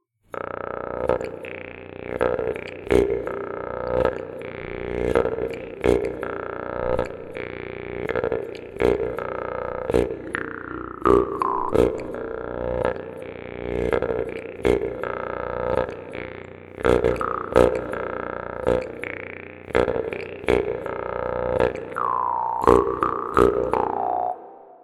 Это варган, 100 грамм. Не лом, не контрабас и не орган, но его масса и упругость обеспечивают в комбинации частоту звучания 25-30 герц.